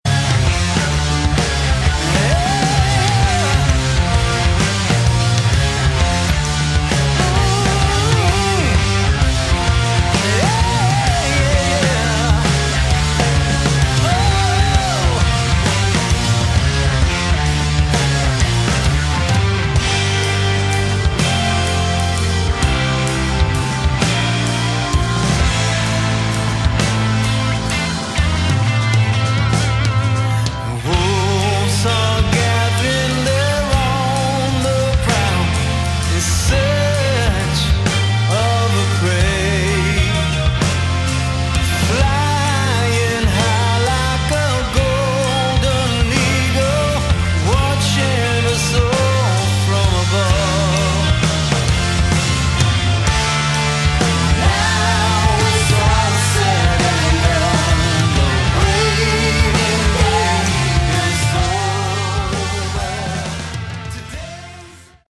Category: Melodic Hard Rock
lead & backing vocals
lead & rhythm guitar, backing vocals
drums, percussion, backing vocals
organ, keyboards, backing vocals